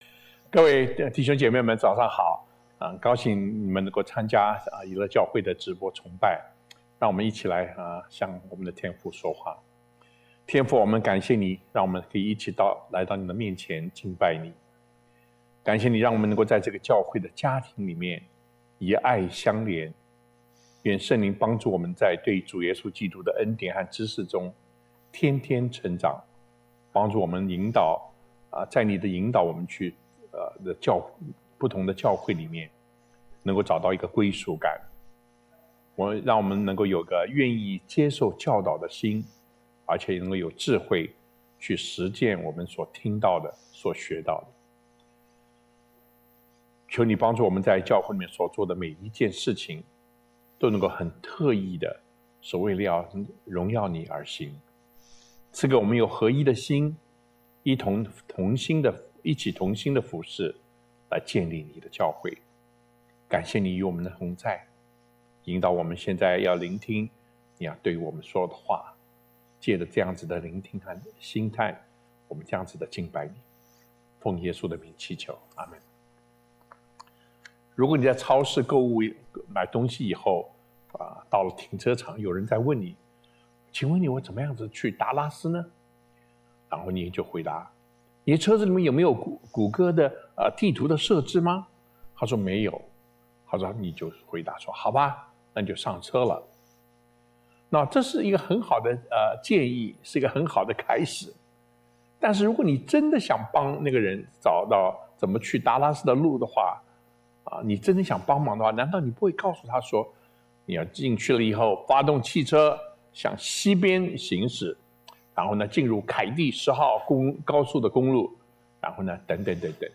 Jireh Bible Church Chinese Sermon Series